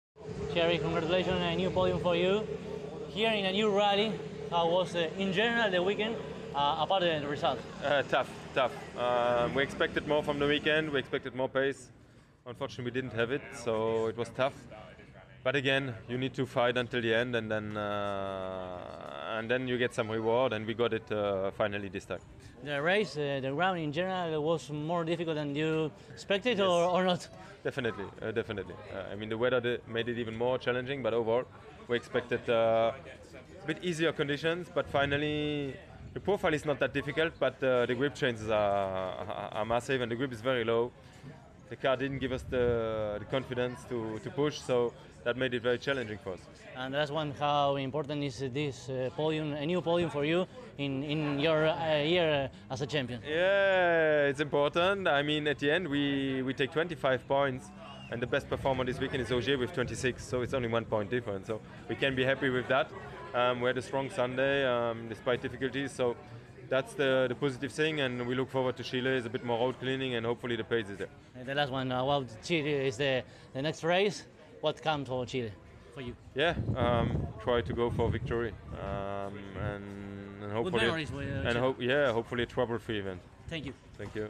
El domingo, luego de la carrera, dialogamos con los tres primeros del clasificador general, que los podrás escuchar en esta nota.
Así entonces, a continuación, podrás escuchar las entrevistas que realizamos (en inglés) con cada uno de ellos: